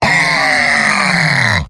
Robot-filtered lines from MvM. This is an audio clip from the game Team Fortress 2 .
{{AudioTF2}} Category:Heavy Robot audio responses You cannot overwrite this file.
Heavy_mvm_cheers02.mp3